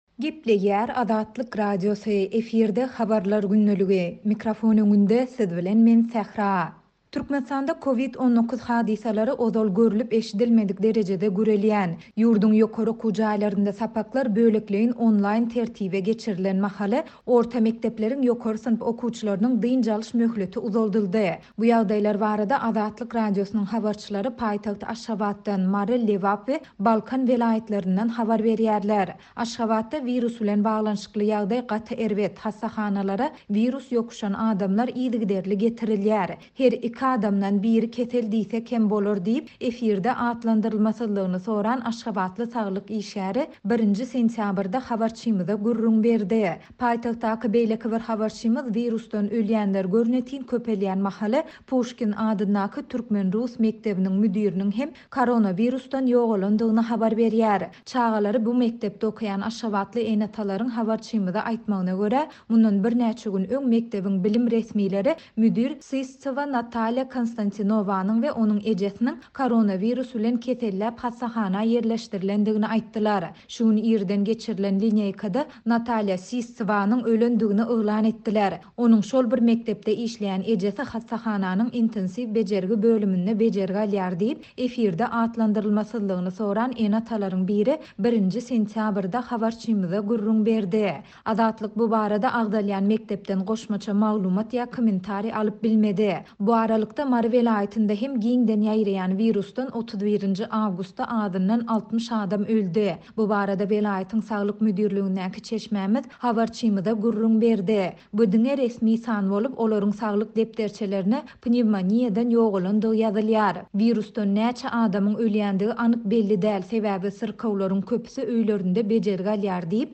Türkmenistanda COVID-19 hadysalary ozal görlüp-eşidilmedik derejede gürelýän, ýurduň ýokary okuw jaýlarynda sapaklar bölekleýin onlaýn tertibe geçirilen mahaly, orta mekdepleriň ýokary synp okuwçylarynyň dynç alyş möhleti uzaldyldy. Bu ýagdaýlar barada Azatlyk Radiosynyň habarçylary paýtagt Aşgabatdan, Mary, Lebap we Balkan welaýatlaryndan habar berýärler.